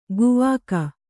♪ guvāka